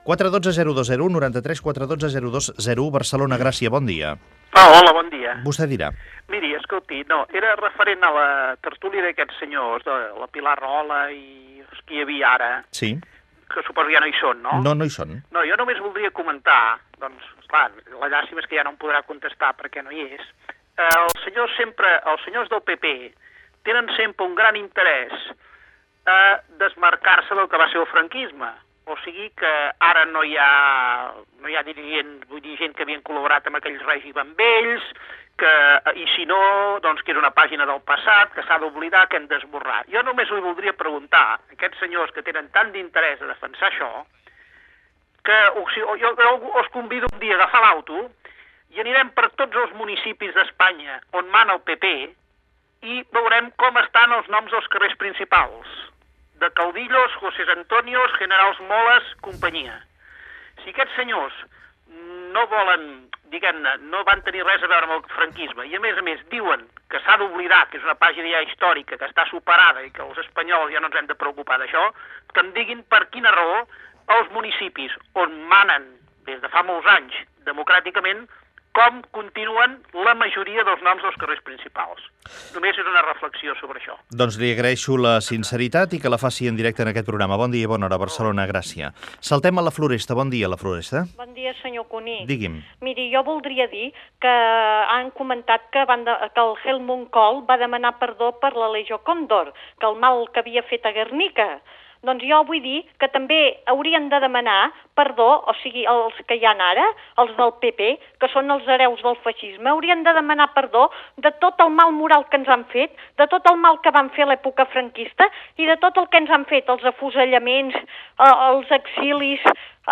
Telèfons del programa i participació telefònica dels oients, després de la tertúlia del programa.
Info-entreteniment